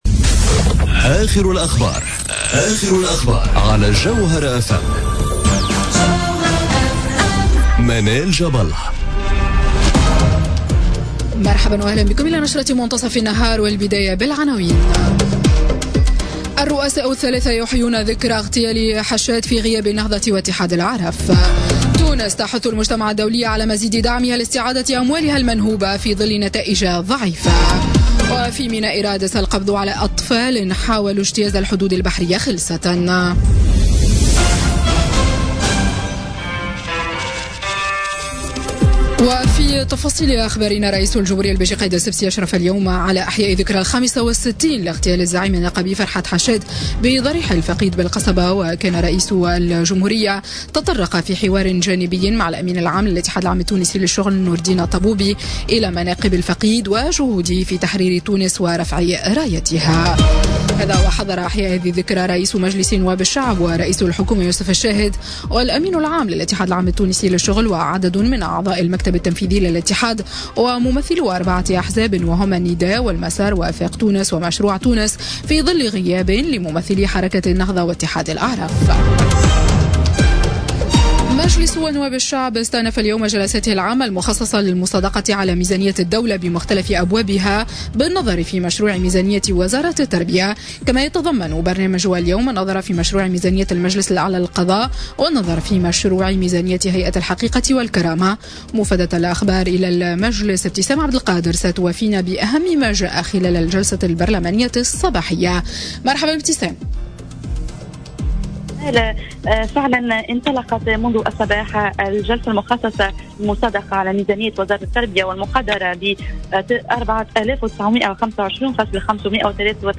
نشرة أخبار منتصف النهار ليوم الثلاثاء 05 ديسمبر 2017